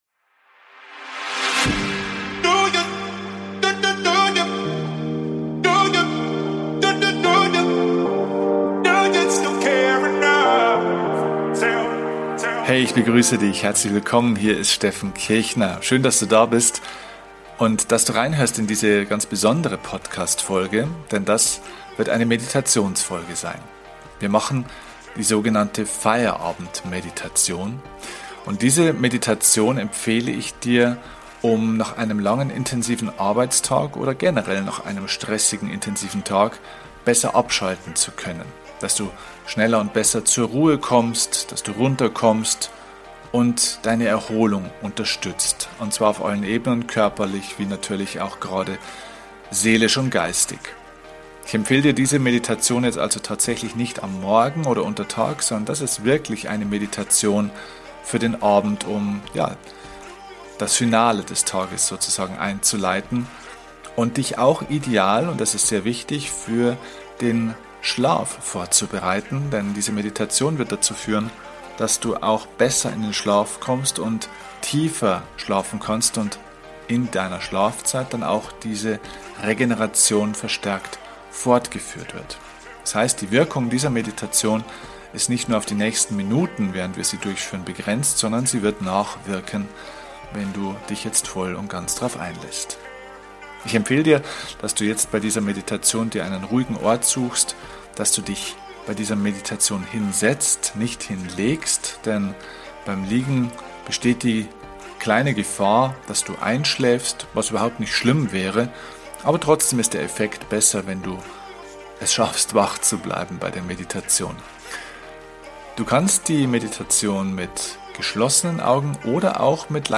Ich habe eine geführte Mediation für Dich, bei der Du es schaffst nach der Arbeit abzuschalten.